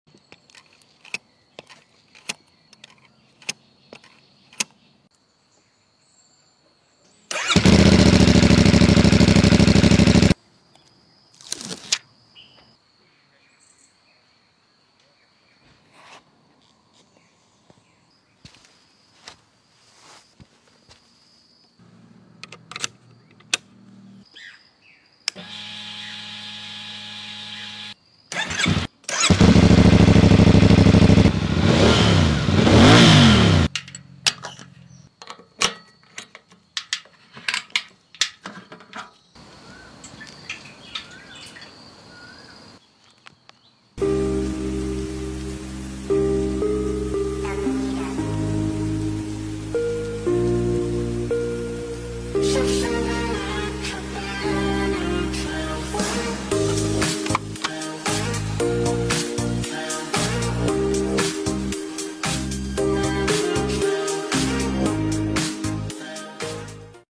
CLC450 Asmr sound effects free download